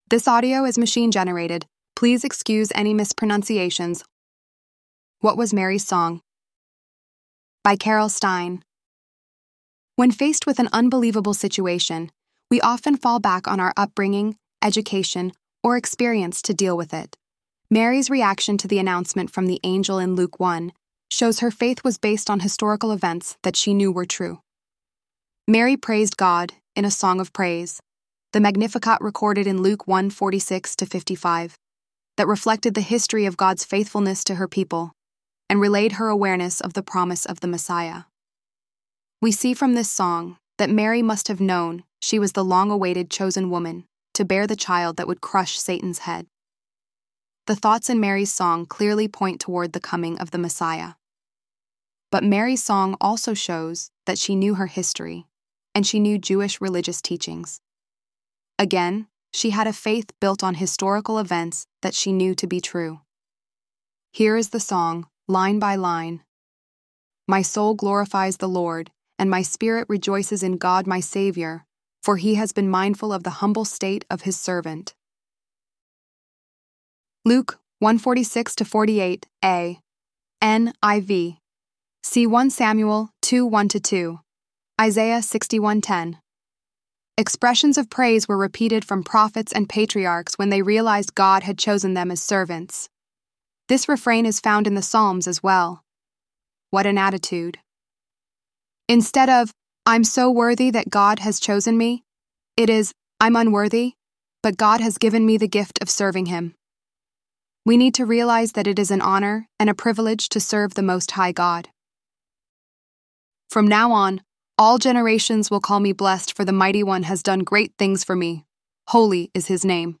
ElevenLabs_12_24.mp3